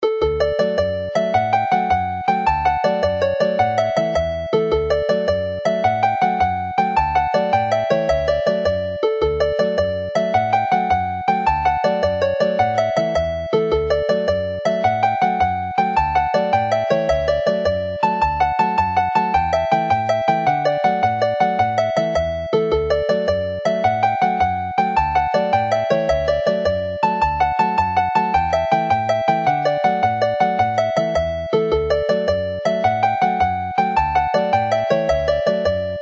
This set of jigs